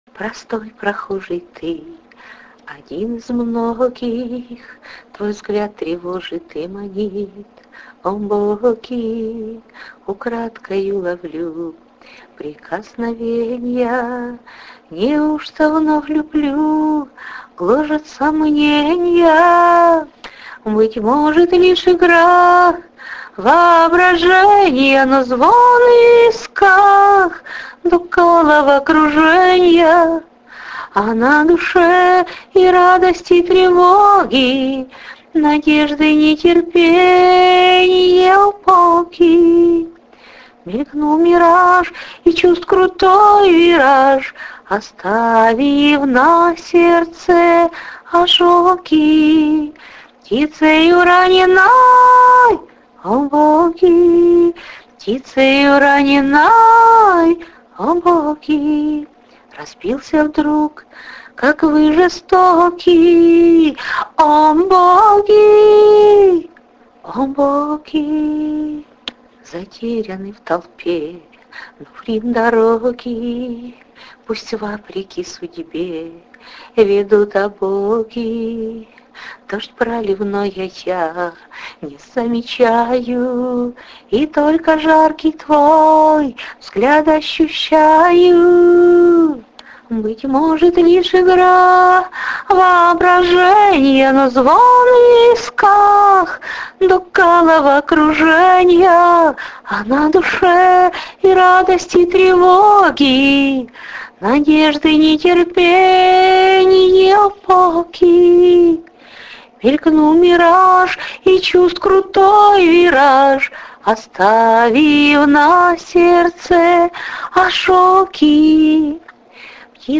Думаю будете разочарованы и исполнением и качеством записи,это было так давно...лет шесть-назад...